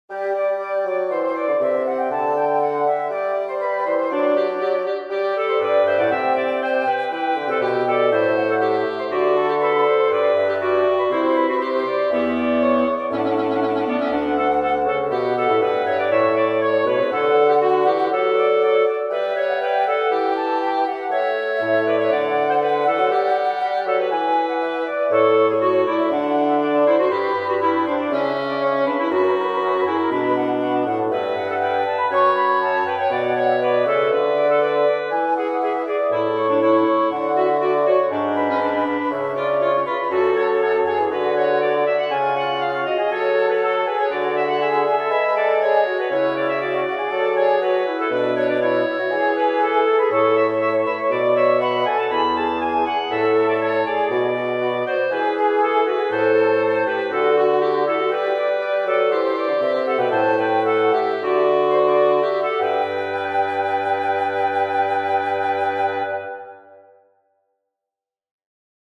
Here is an invention for wind trio in G major.